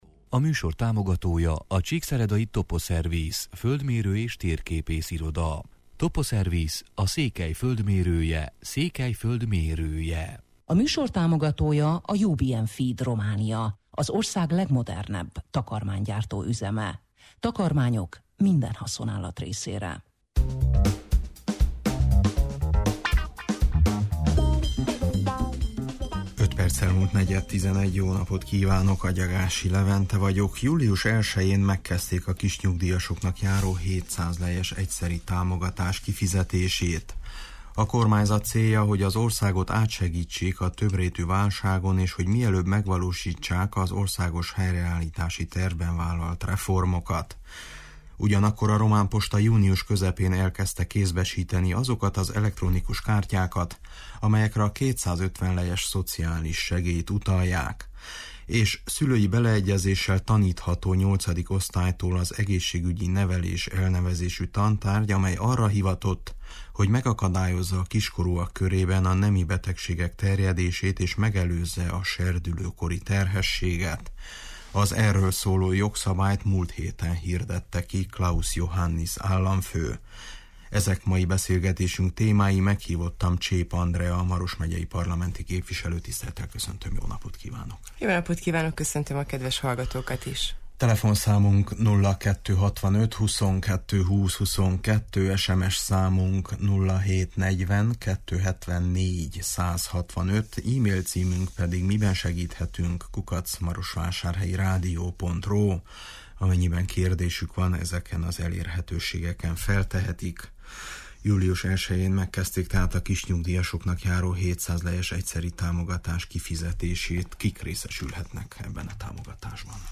Csép Andrea Maros megyei parlamenti képvislőt kérdeztük: